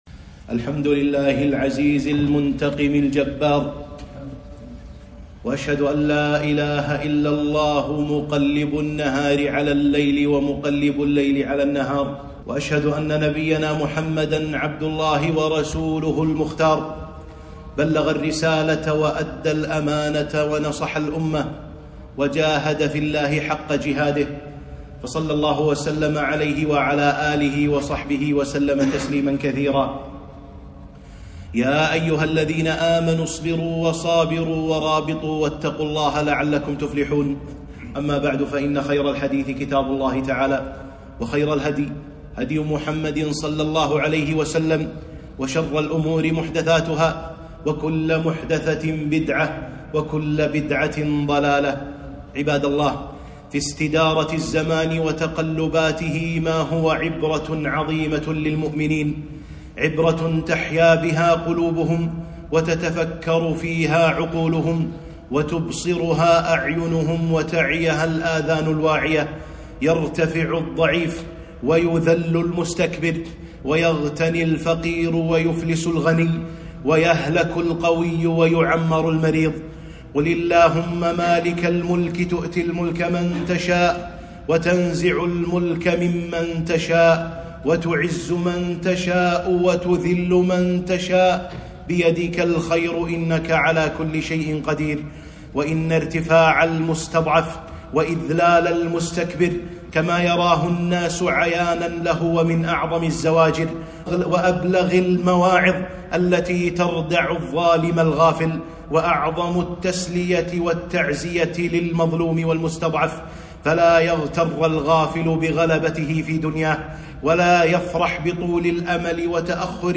خطبة - عاقبة المجرمين وانتصار المظلومين